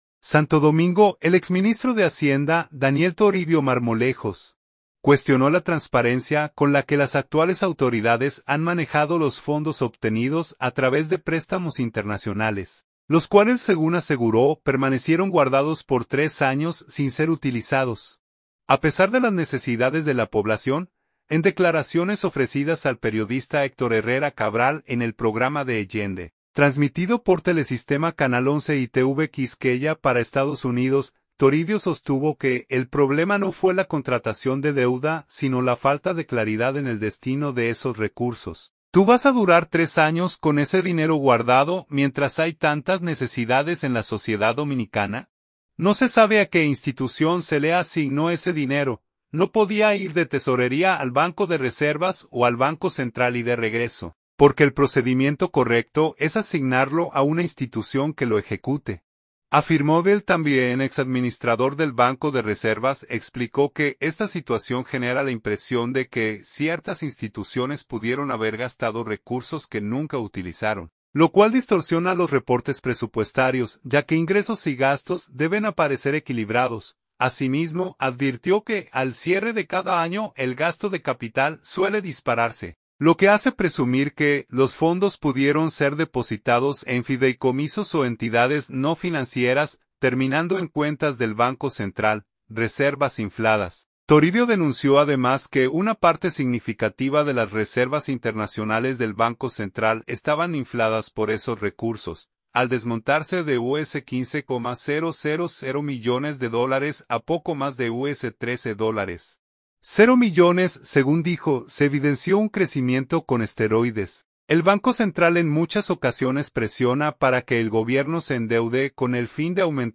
en el programa D’AGENDA, transmitido por Telesistema canal 11 y TV Quisqueya para Estados Unidos